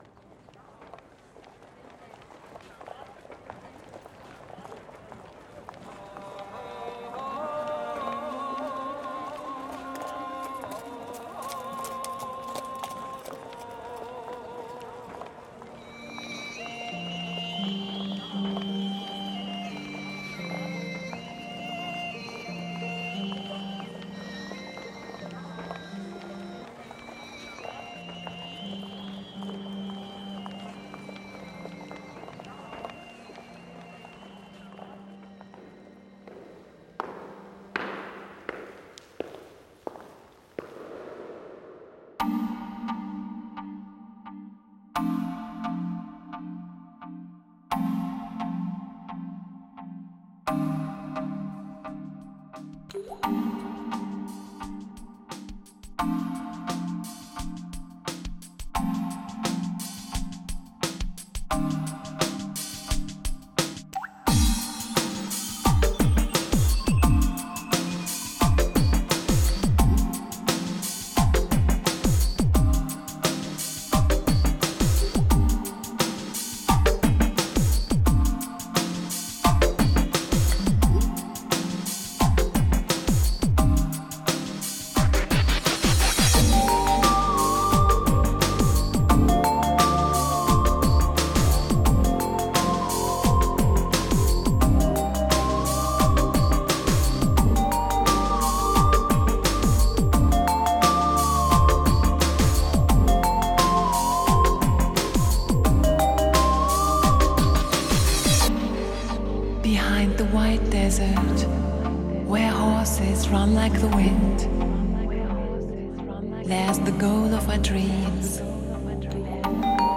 洗涤灵魂、震撼心耳、神般呢喃的天籁与美境，带你无限遨游超凡脱俗的天地与宇宙